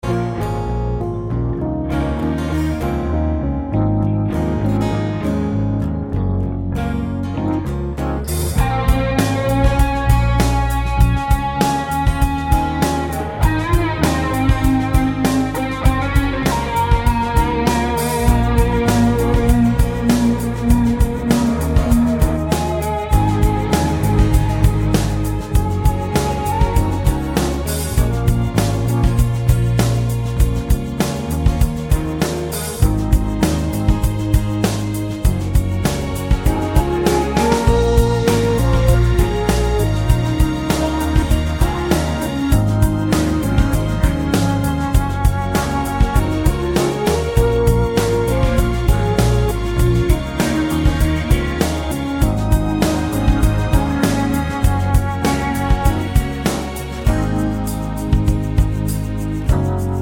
no Backing Vocals Indie / Alternative 4:38 Buy £1.50